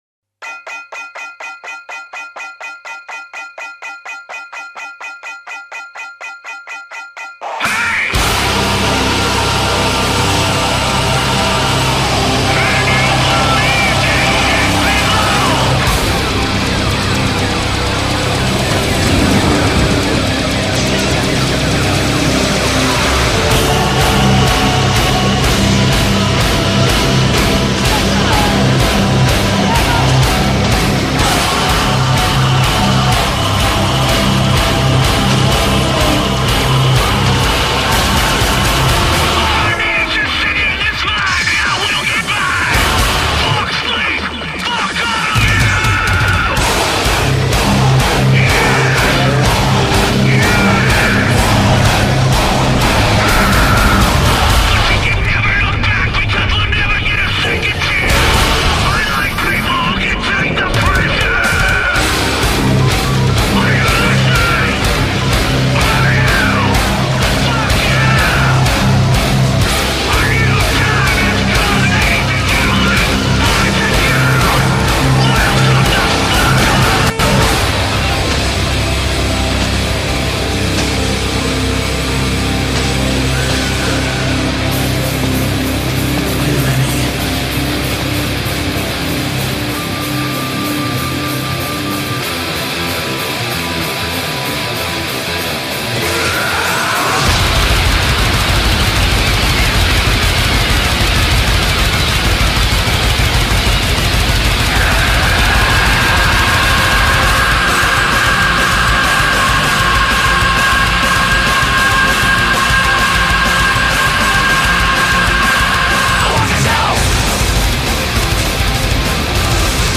Actually - the music to this is entirely appropriate.